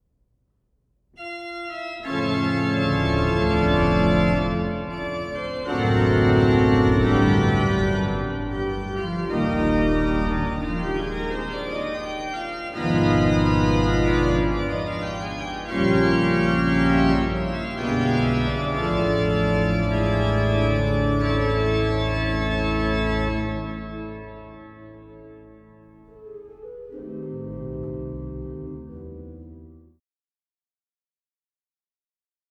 Walcker-Orgel in der St. Annenkirche in Annaberg-Buchholz